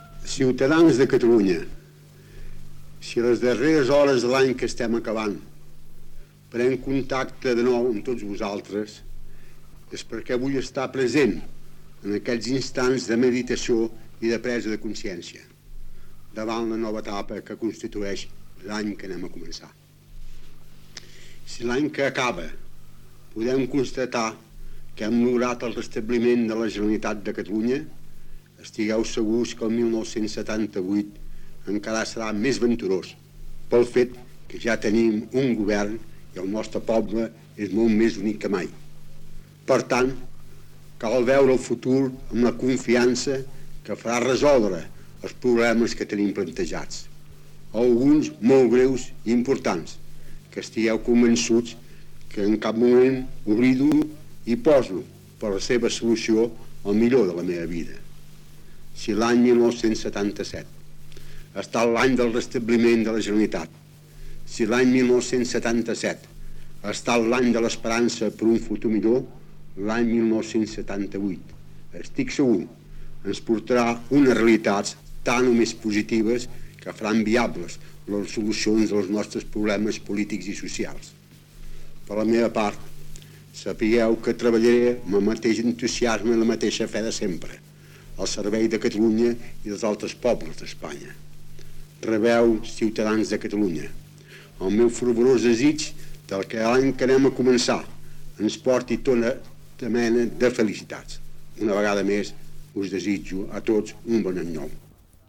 Primer discurs de cap d'any del president de la Generalitat Josep Tarradellas
Informatiu